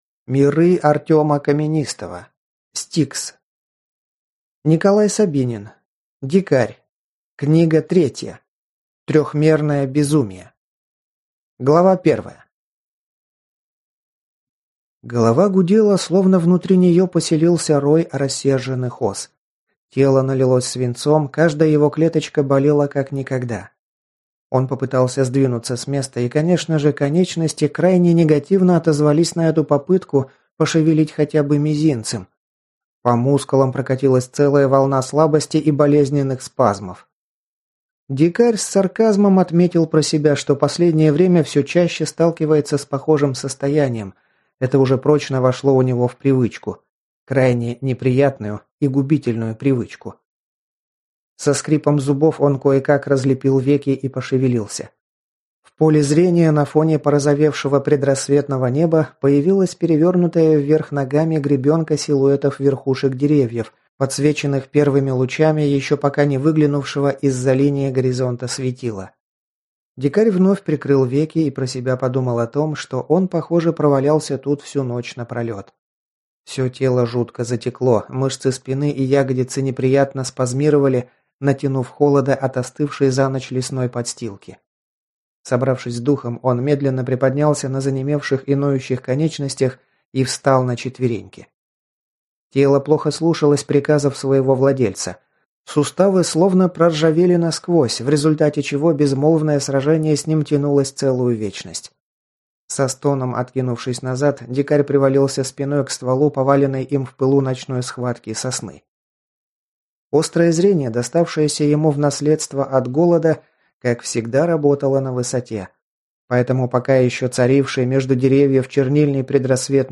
Аудиокнига S-T-I-K-S. Дикарь. Трехмерное безумие | Библиотека аудиокниг